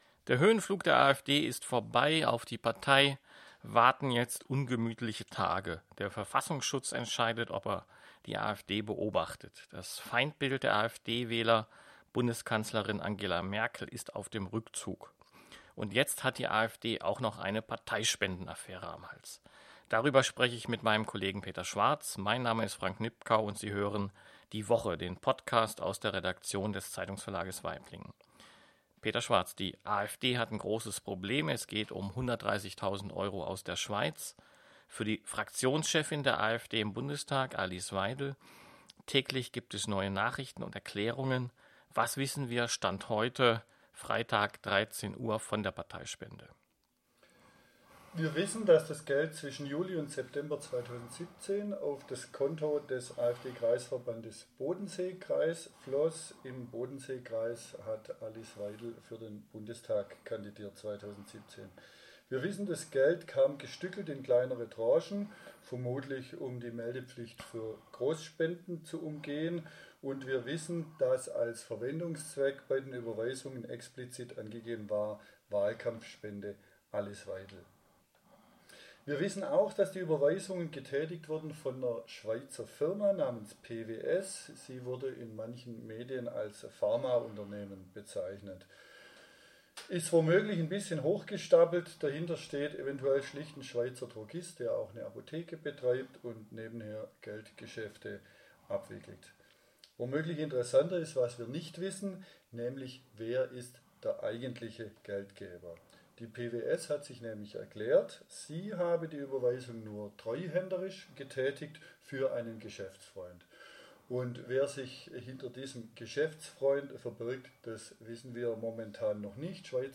Der Verfassungsschutz entscheidet, ob er die AfD beobachtet. Das Feindbild der AfD-Wähler, Bundeskanzlerin Angela Merkel ist auf dem Rückzug. 17 Minuten 15.92 MB Podcast Podcaster Die Woche Die Woche ist der Nachrichten-Podcast aus dem Zeitungsverlag Waiblingen.